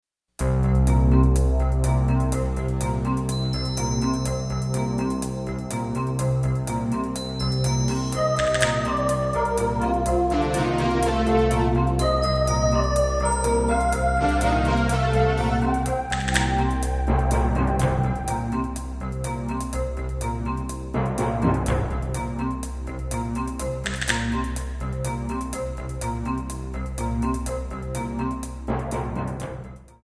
Backing track files: 1980s (763)